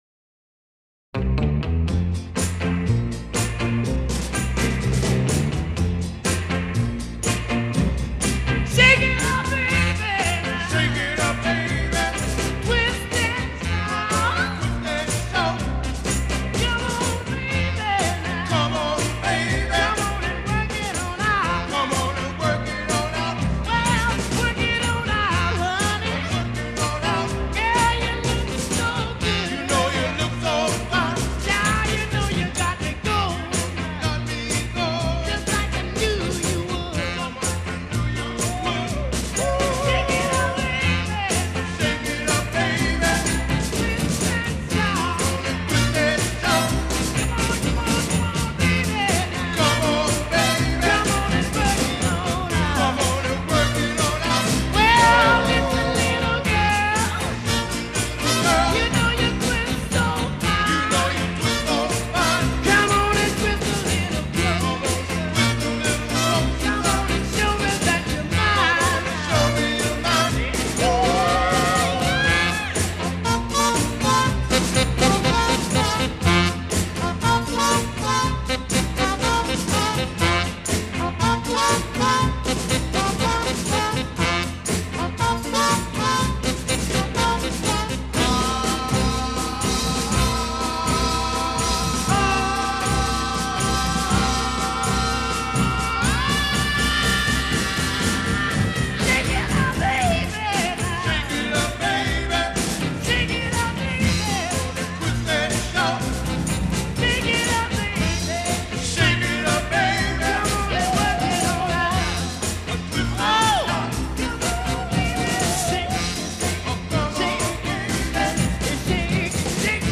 Recorded in New York, April 1962
A Intro* : 8 trumpet elaboration on opening theme
B chord : 6 build a 9th chord and tension
A Verse+ : 24+ vocals build and elaborate on original verse